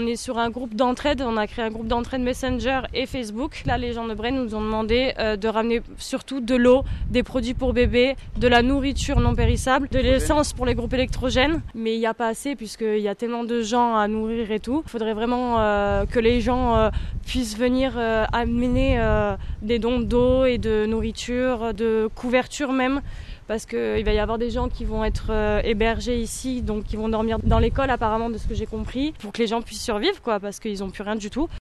Reportage Sud Radio